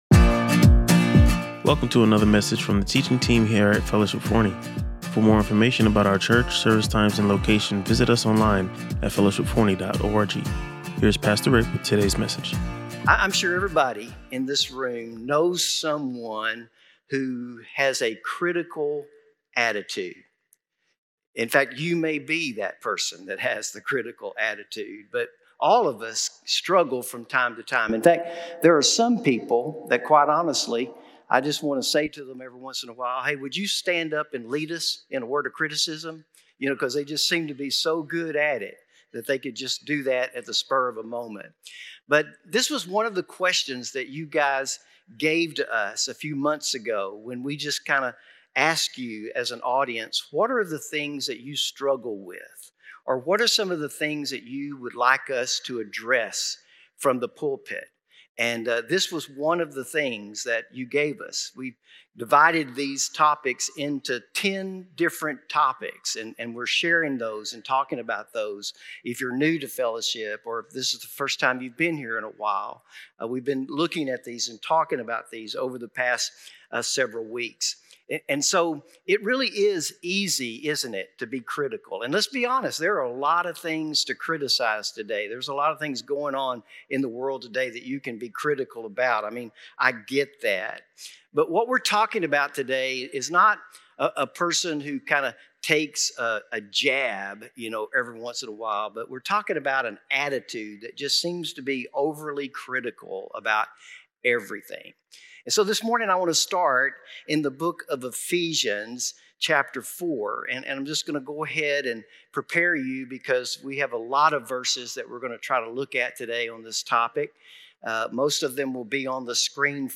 Drawing from Ephesians 4 and Galatians 5, he emphasized how Christians must replace negative attitudes with the fruit of the Spirit – including love, joy, peace, and self-control. He addressed three key questions about controlling anger, avoiding bitterness, and self-forgiveness, emphasizing that our identity is not based on our struggles but on who we are in Christ. Listen to or watch the full sermon to learn practical steps for letting go of criticism and embracing God’s grace.